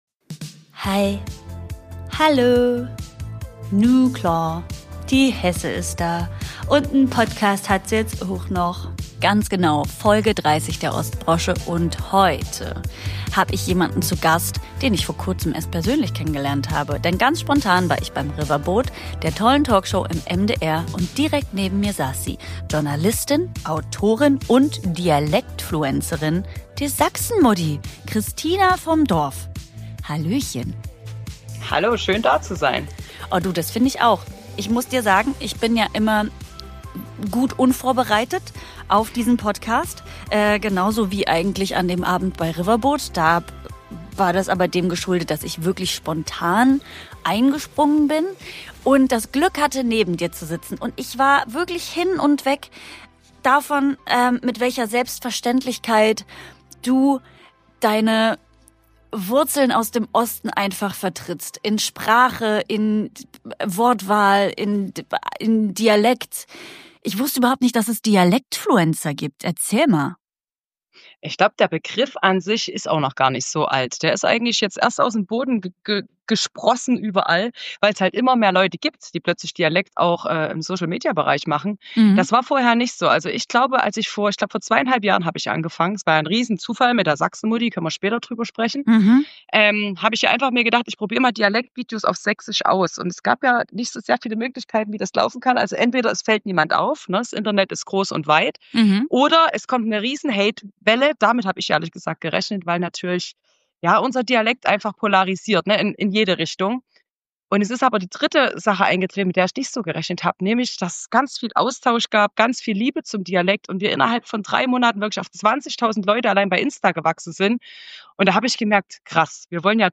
Wir quatschen wild drauf los über ihre Kindheit zwischen Trecker und Tresen, übers Mama-Sein und darüber, warum Sächsi sein & Authentizität im Netz so selten aber so wichtig ist. Folge 30 der Ostbrosche über Bodenhaftung, Selbstbewusstsein, Ossi-Charme und die Freiheit, einfach man selbst zu bleiben. Hör rein, wenn du echte Geschichten aus dem Osten liebst – mit Dialekt, Gefühl und einer guten Portion Selbstironie.